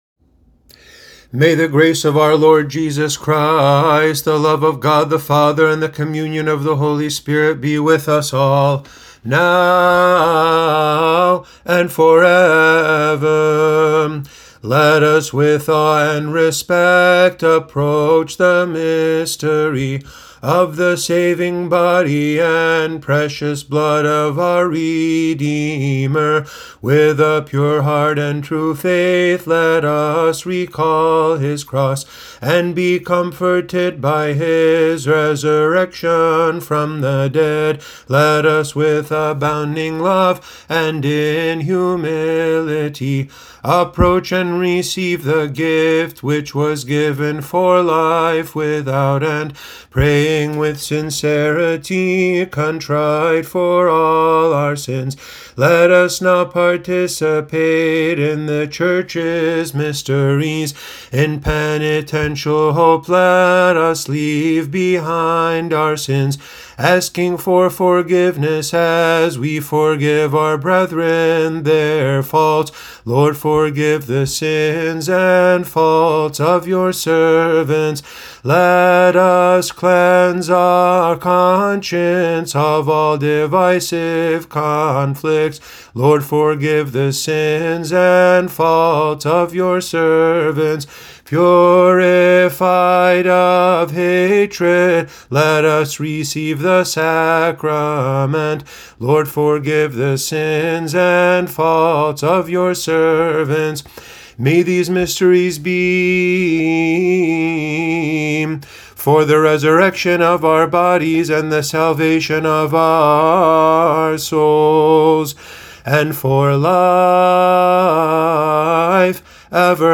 They’re set to the traditional melodies, but in English, with close attention given to the stress of the syllables so it sounds as not-awkward as possible.
Please excuse the quality of my voice.